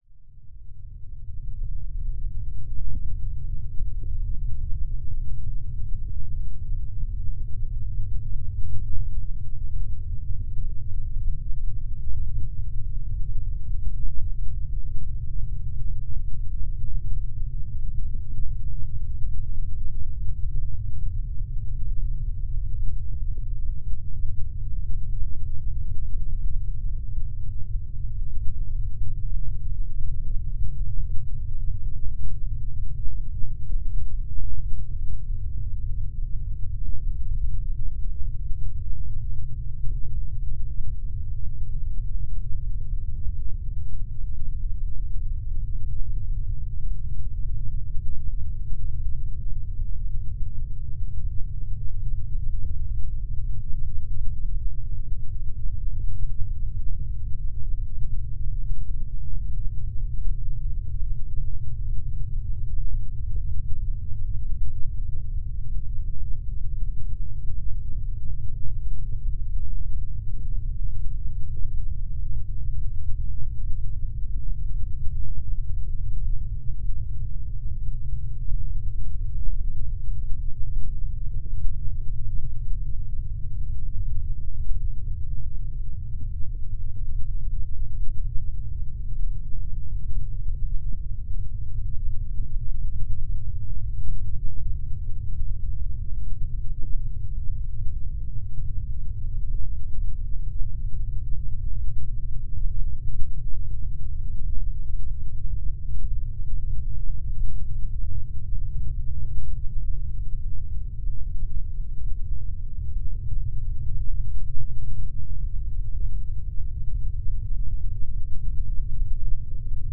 Здесь вы найдете жуткие скрипы полов, завывание ветра в broken окнах, шепот пустых комнат и другие эффекты.
Жуткие атмосферные звуки заброшенного дома или склада